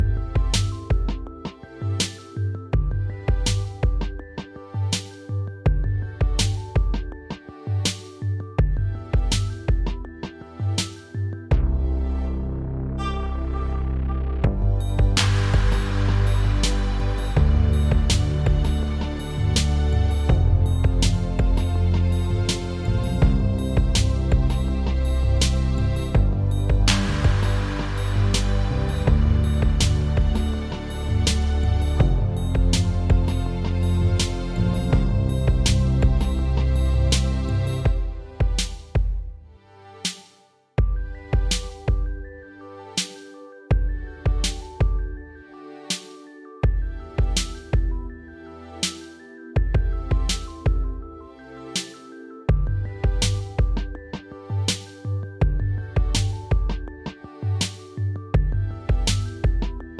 Minus Main Guitars For Guitarists 4:04 Buy £1.50